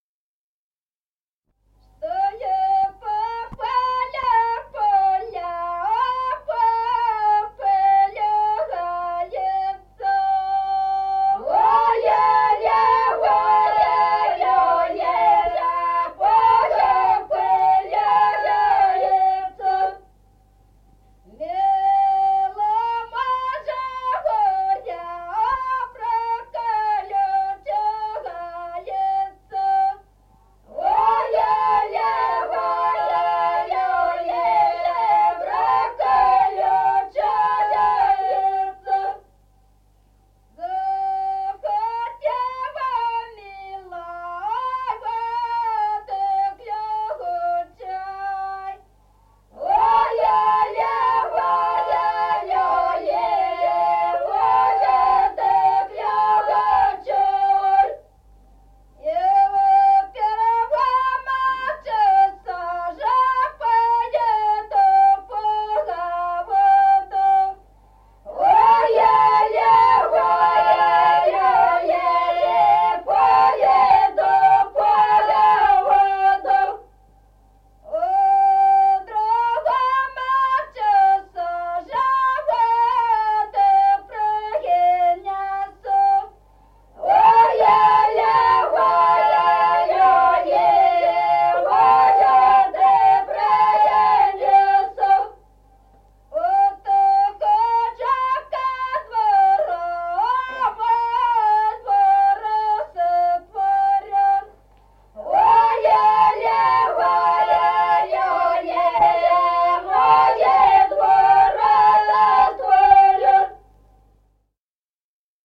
Народные песни Стародубского района «Чтой по полю пыль», карагодная.
1953 г., с. Остроглядово.